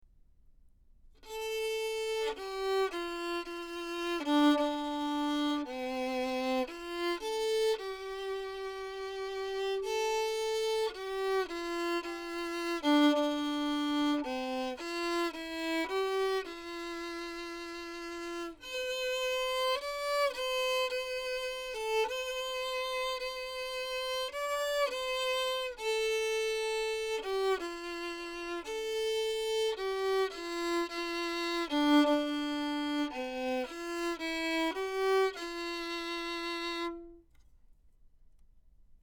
Type: 3-Capsule Small Diaphragm Condenser – Cardiod/SuperCardiod/Omni
Violin, omni-directional (24 centimeters from F-hole)
021-Little-Gem-Omni-Violin-Lyric-24cm-from-F-hole.mp3